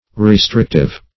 Restrictive \Re*strict"ive\, a. [Cf. F. restrictif.]